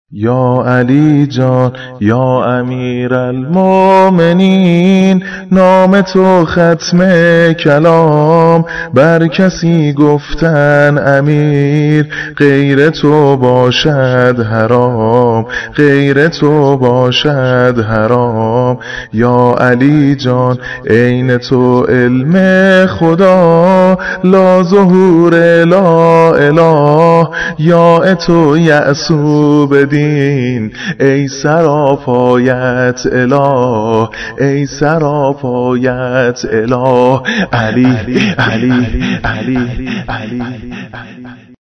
عید غدیر
شور